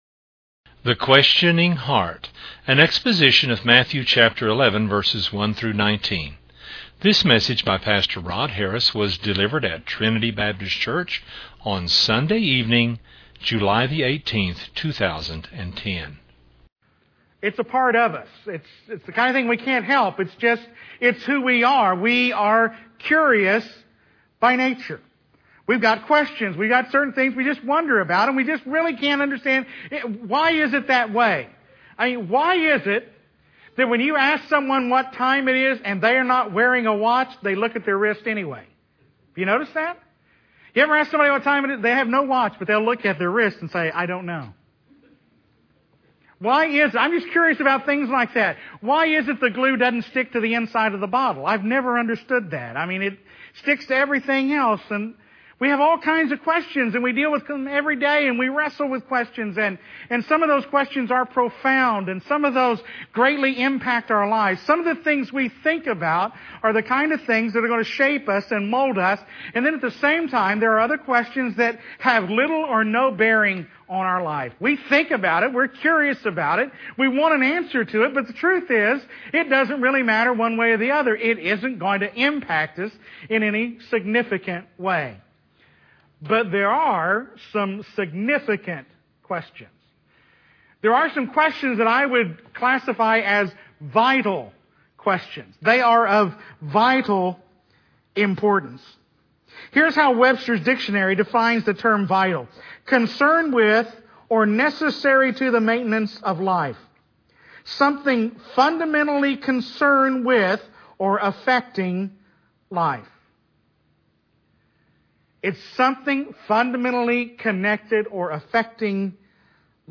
was delivered at Trinity Baptist Church on Sunday evening